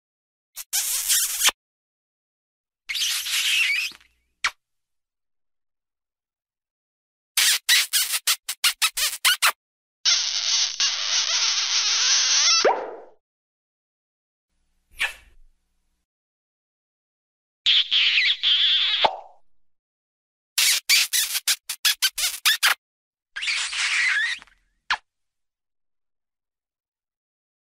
دانلود آهنگ بوس 2 از افکت صوتی انسان و موجودات زنده
دانلود صدای بوس 2 از ساعد نیوز با لینک مستقیم و کیفیت بالا
جلوه های صوتی